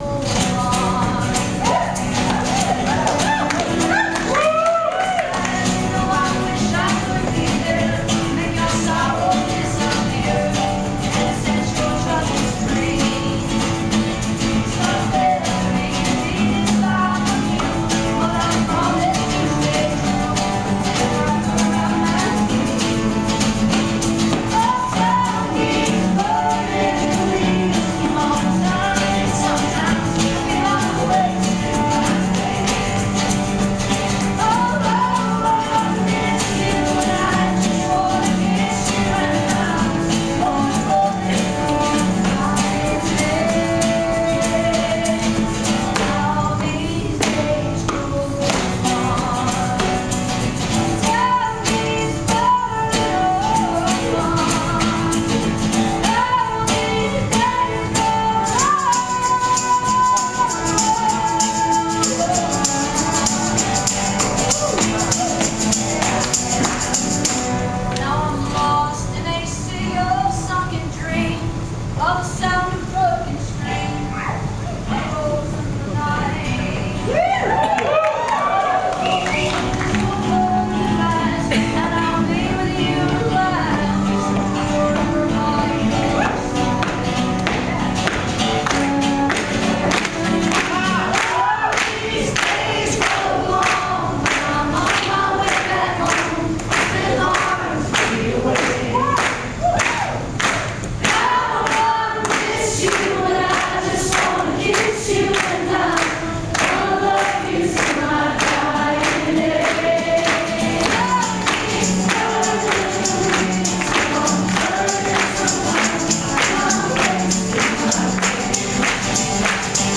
(captured from youtube videos)
(completely unplugged)